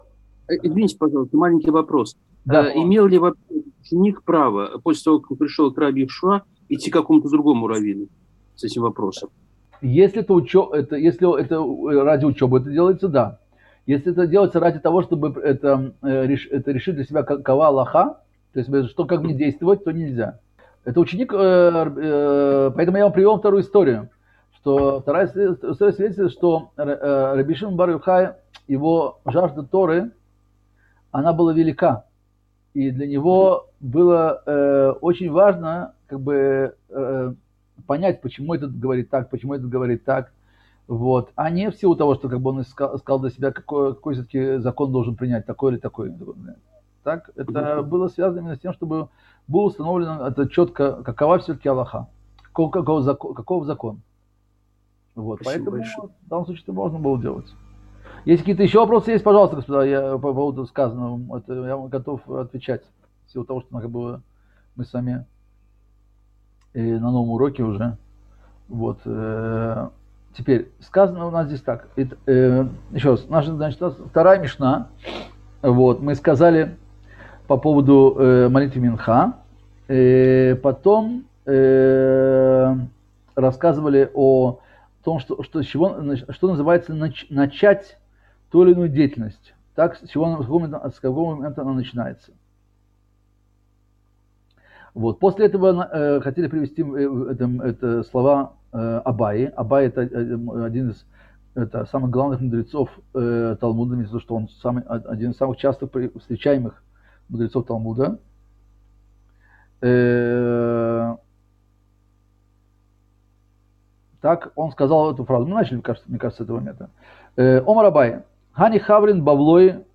Цикл уроков по изучению мишны Шаббат